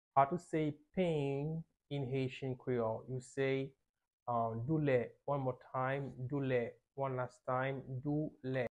How to say Pain in Haitian Creole - Doulè pronunciation by a native Haitian Teacher
“Doulè” Pronunciation in Haitian Creole by a native Haitian can be heard in the audio here or in the video below:
How-to-say-Pain-in-Haitian-Creole-Doule-pronunciation-by-a-native-Haitian-Teacher.mp3